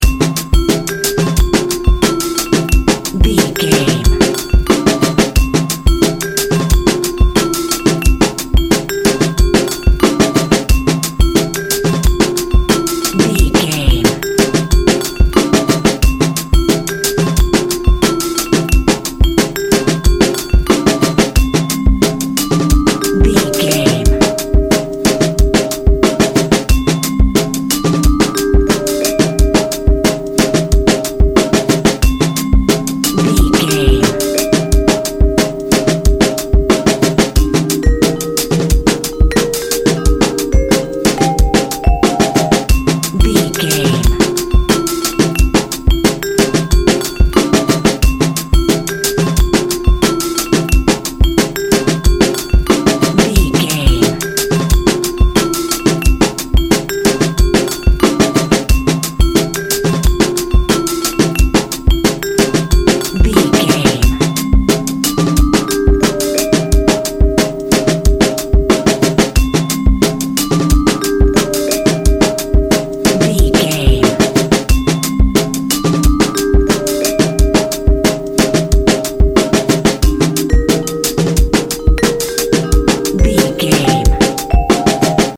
Ionian/Major
instrumental music
synths
synth lead
synth bass
synth drums